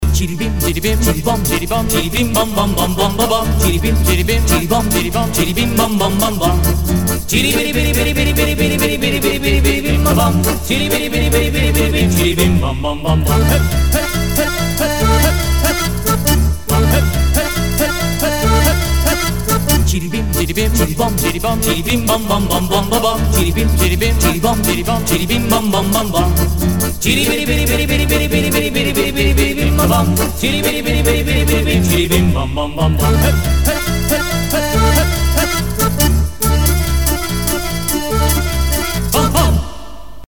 • Качество: 320, Stereo
ретро
Еврейские